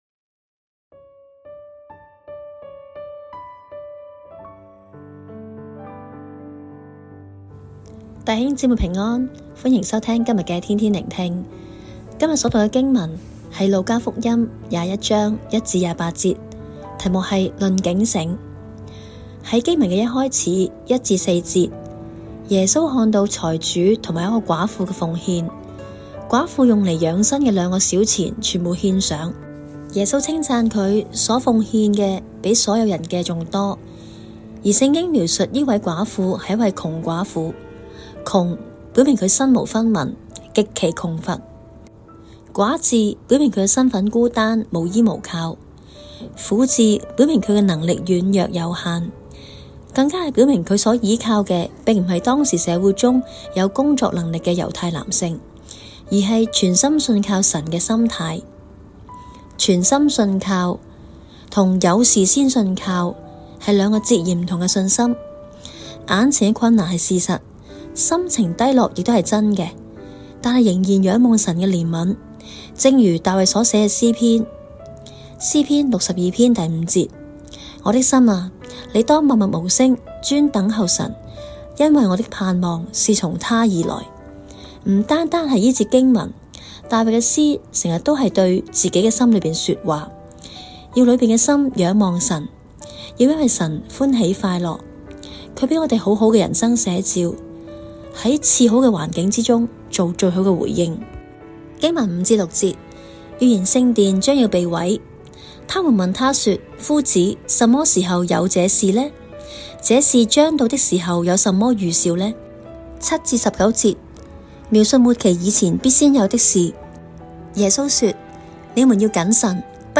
粵語錄音連結🔈